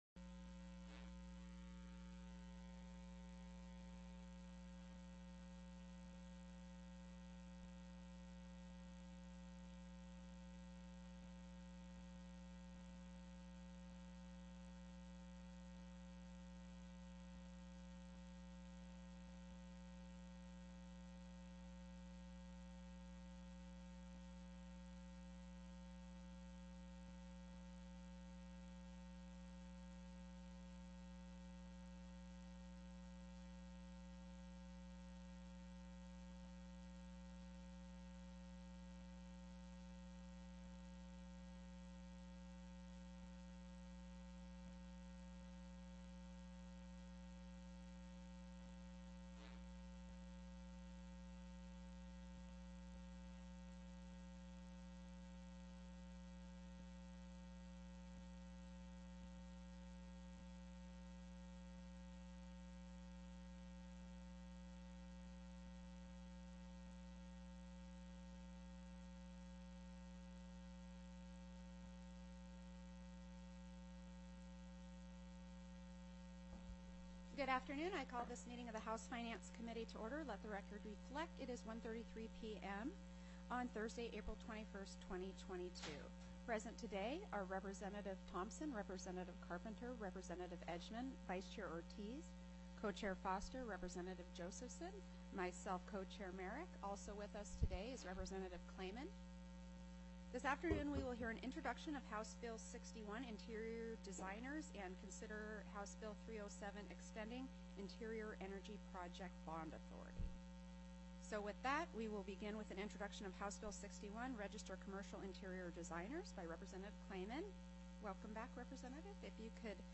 The audio recordings are captured by our records offices as the official record of the meeting and will have more accurate timestamps.
Public Testimony
PRESENT VIA TELECONFERENCE